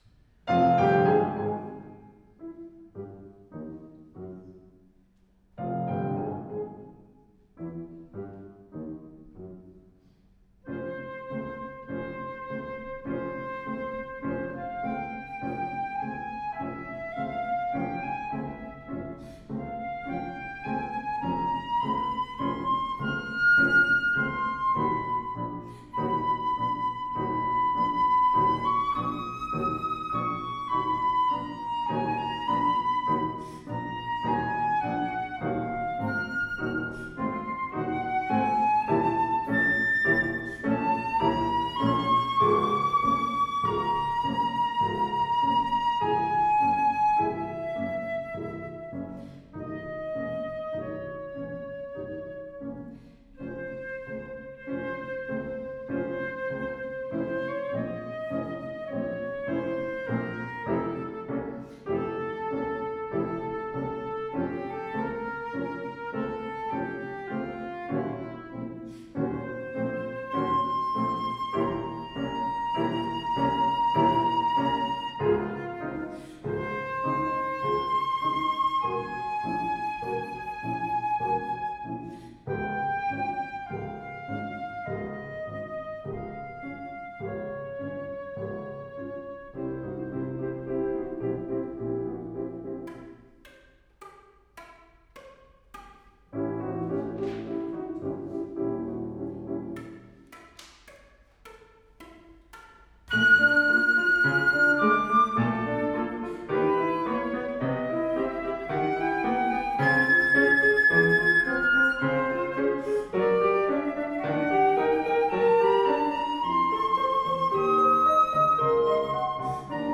Absolventsky_koncert